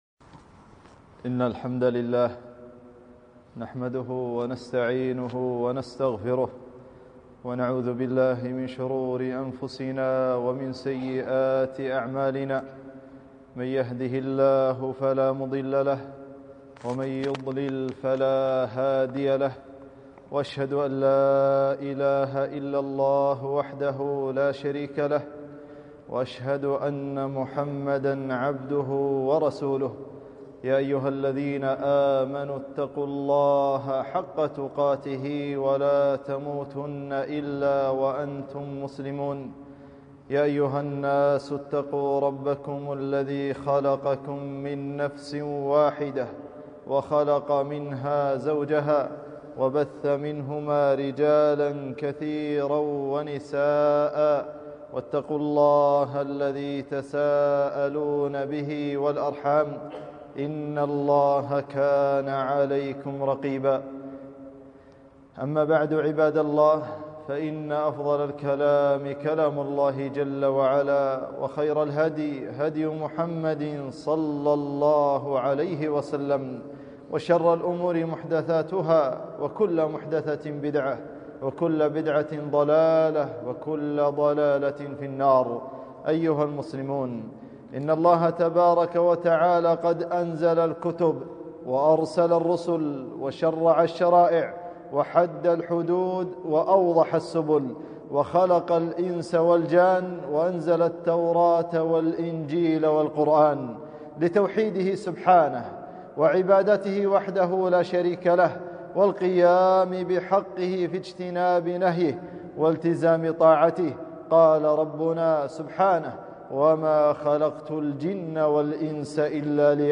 خطبة - خطر الشرك و أنواعه